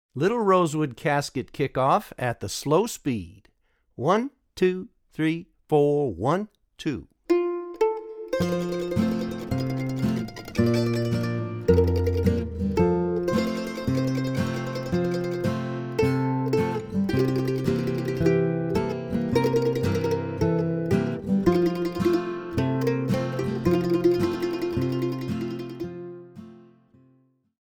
DIGITAL SHEET MUSIC - MANDOLIN SOLO
Traditional Mandolin Solo
Online Audio (both slow and regular speed)